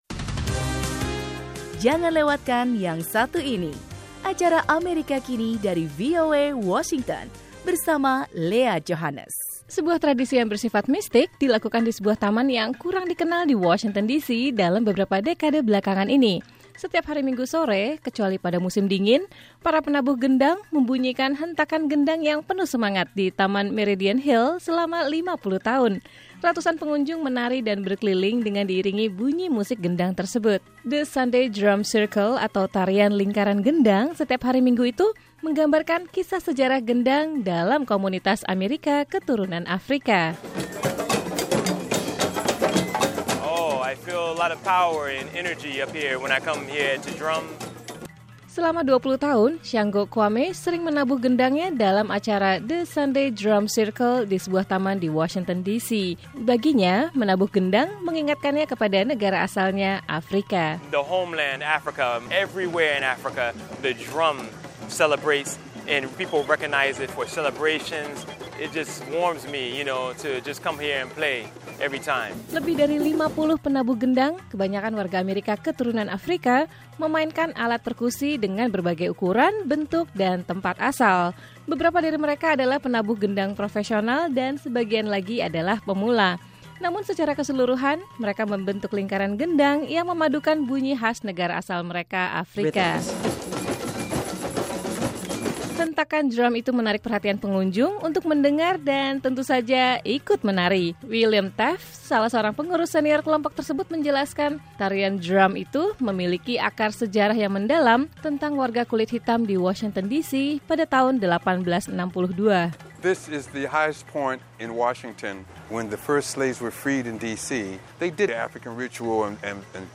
Sebuah tradisi yang bersifat mistik dilakukan di Taman Meridian Hil, Washington DC dalam beberapa dekade belakangan ini. Setiap Minggu sore, kecuali pada musim dingin, para penabuh gendang membunyikan hentakan gendang dengan penuh semangat.